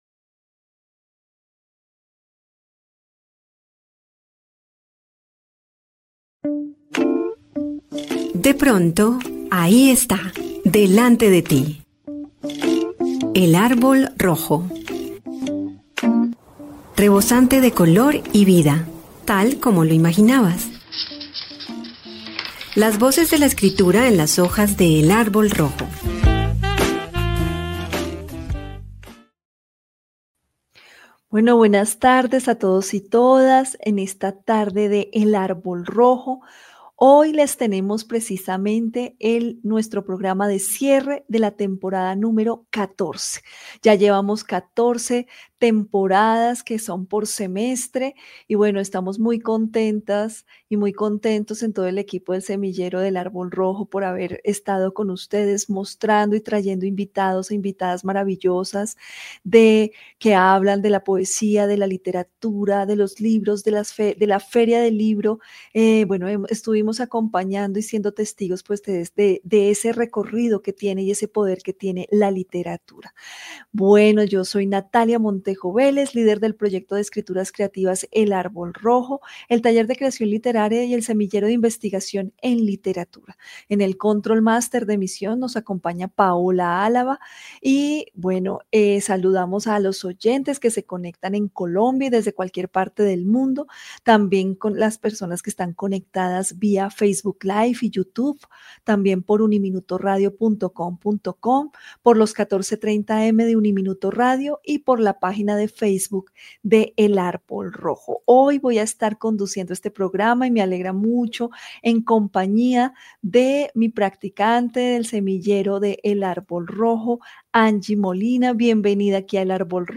La conversación cargada de sensibilidad fue un viaje por los caminos que recorren el cuerpo, la música, la infancia y la historia colonial.